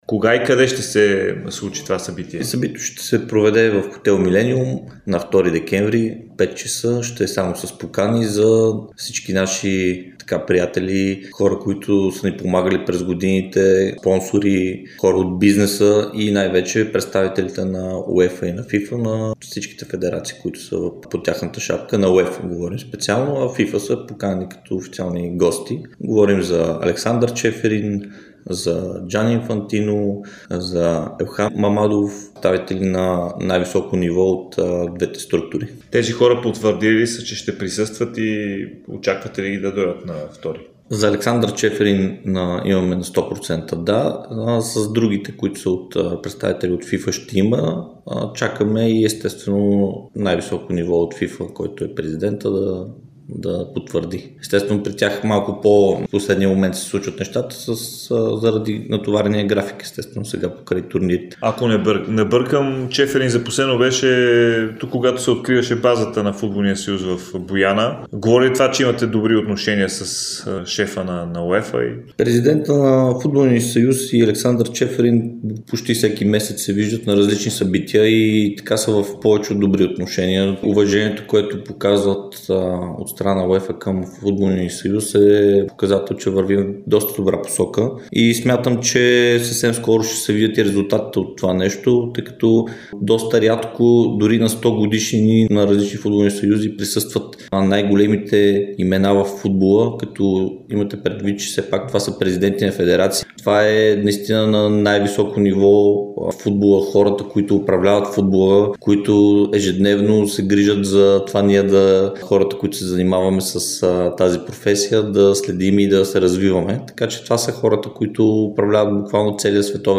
интервю пред Дарик радио и dsport